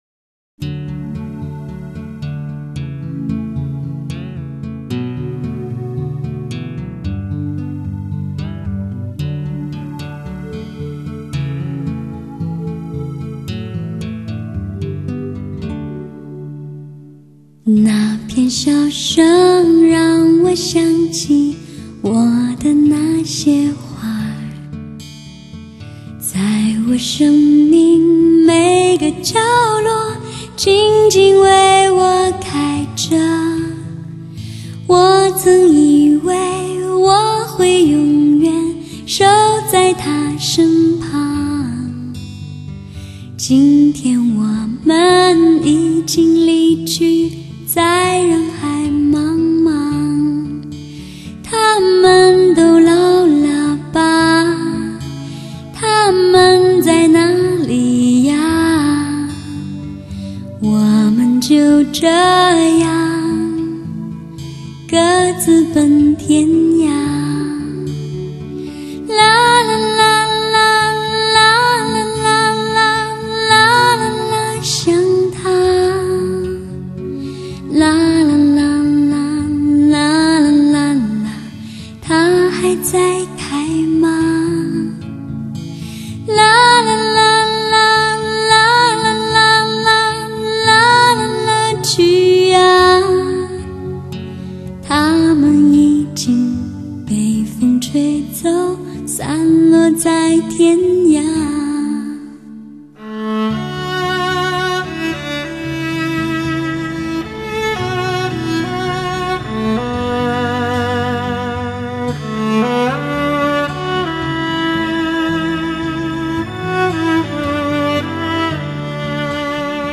纯净清新的声音质感  顶级发烧的录音品质
独特自然的编配手法  平衡清晰的聆听感受